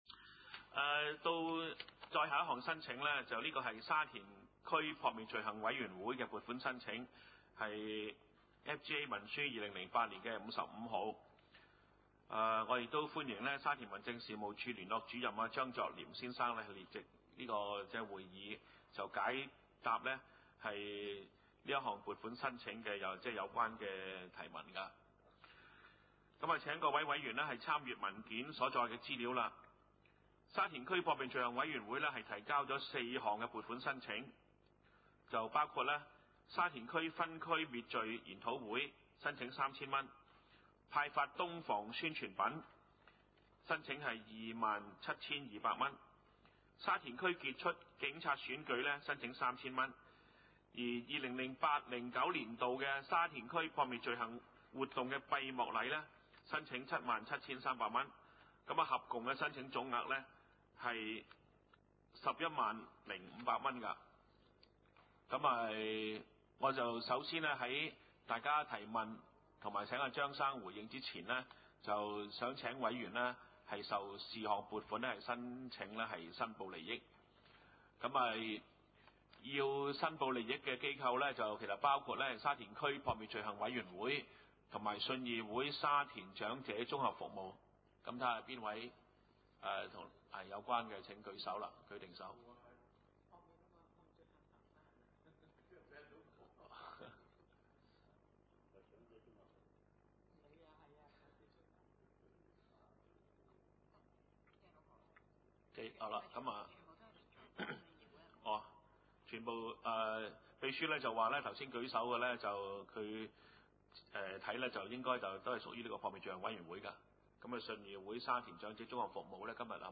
: 沙田區議會會議室